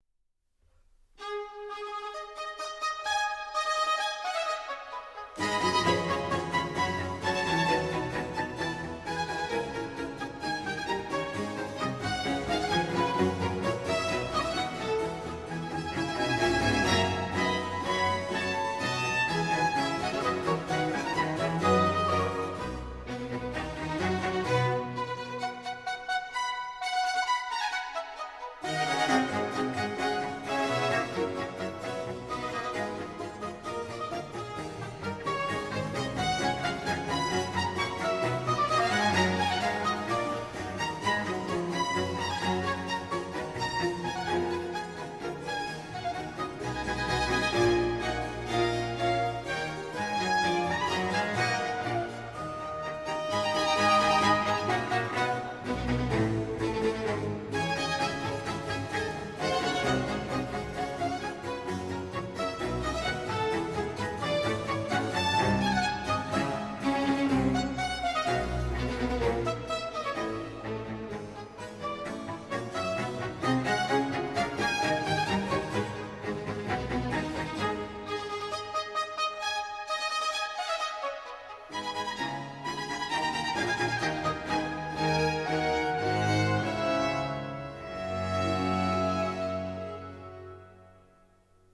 barokní housle
barokní viola
barokní violoncello
loutna, barokní kytara
kontrabas